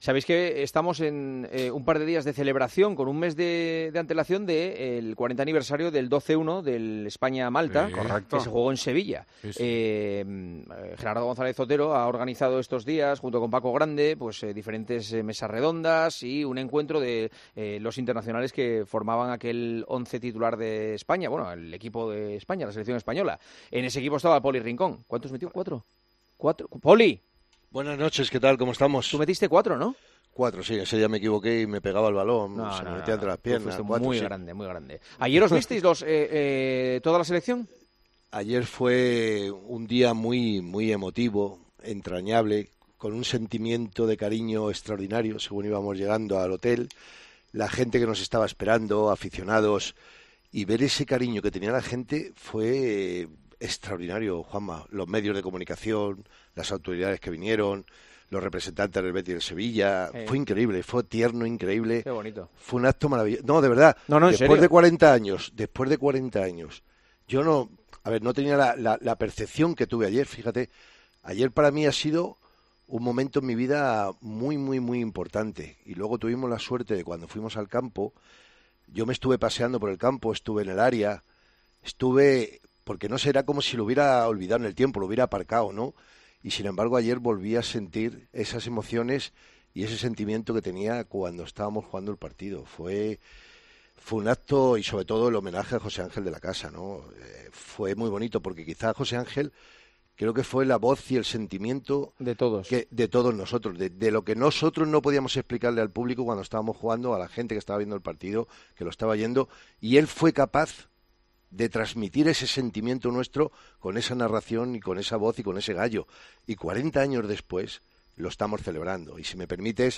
Juanma Castaño habló con Poli Rincón , autor de cuatro goles en ese partido y comentarista de Tiempo de Juego, sobre cómo ha sido el homenaje celebrado en Sevilla.